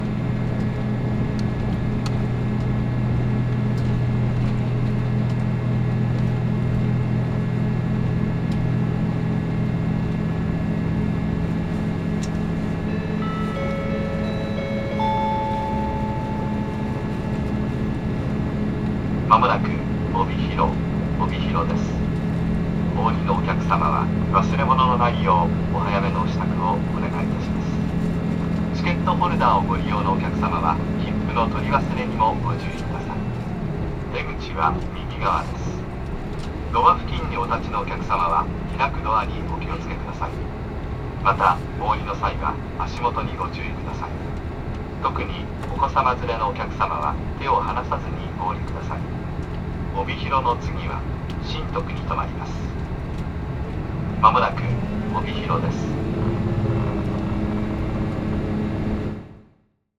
列車はひたすらノンストップで大地を駆け抜ける。
そして帯広。到着のアナウンスが流れると、席を立って荷物をまとめ始める乗客の姿が、あちこちに見られた。
0010ikeda-obihiro.mp3